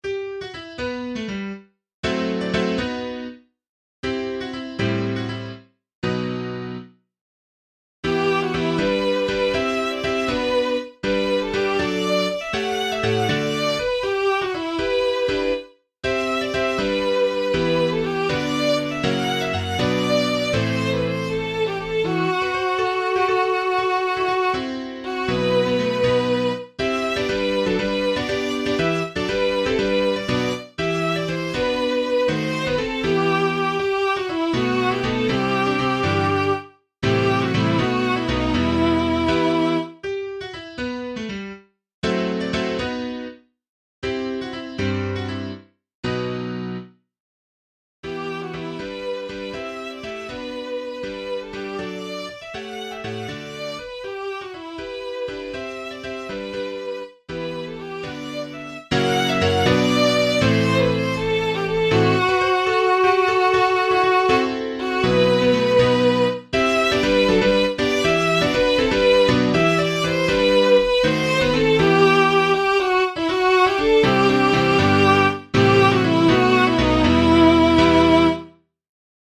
Recordings are synthesized, so they have some wrong timings, very limited phrasing and dynamics, and had to substitute violin soundfont for original vocal line just to get a rough idea of the flow.
Click here to play or download an mp3 audio recording of “Irish Love Song” (will sound mechanical, as this is merely scanned from old sheet music, then vocal line assigned to violin soundfont)
Irish_Love_Song__violin.mp3